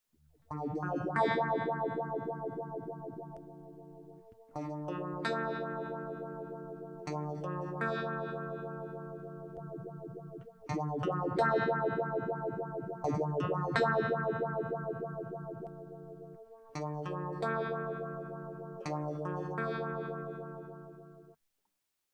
Enregistrer directement dans une table yamaha MG-12/4, avec une carte ECHO MIA MIDI.
Le son est neutre,sans traitement.
BRIGHT MOD ON->OFF->ON->OFF :